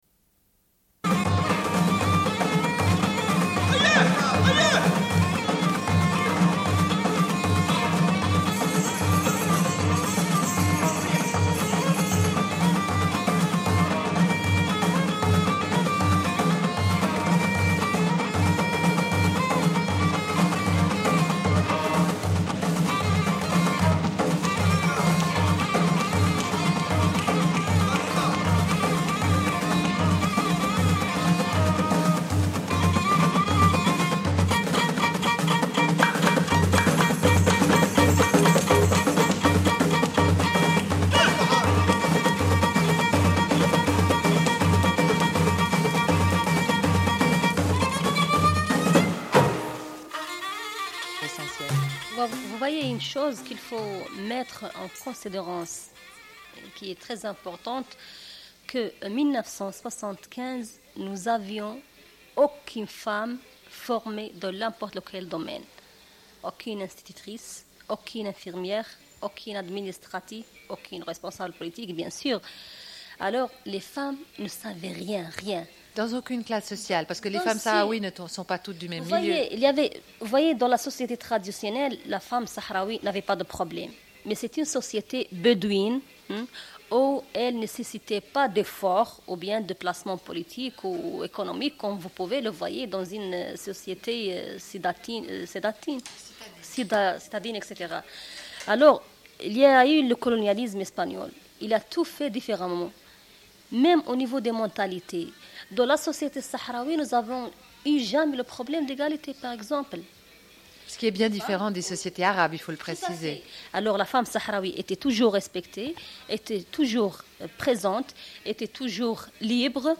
Une cassette audio, face B29:00